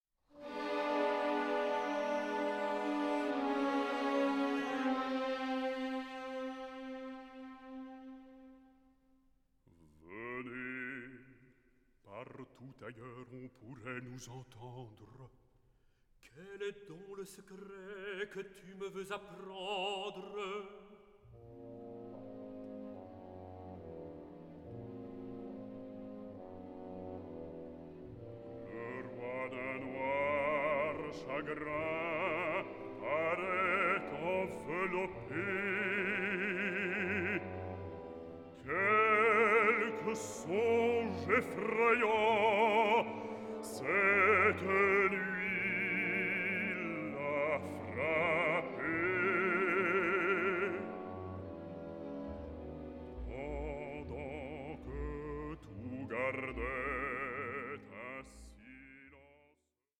Choeur